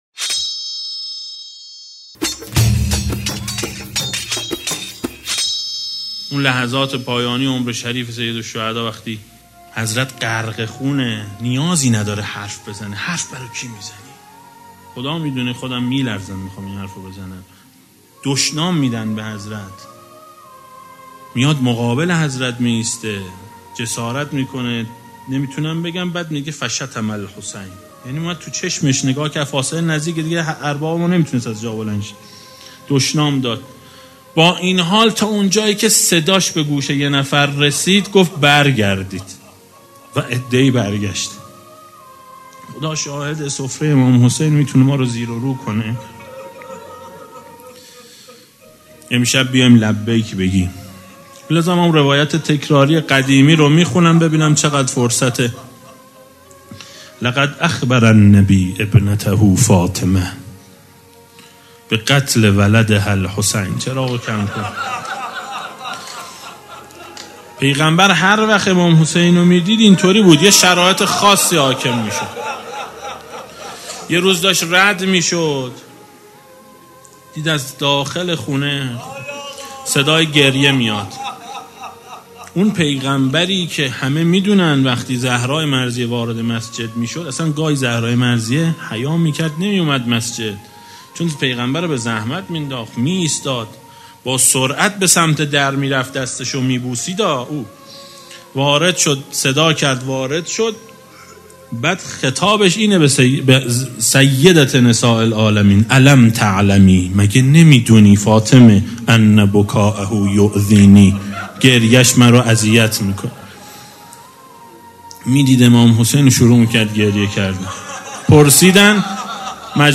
روضه شب اول محرم سال 1394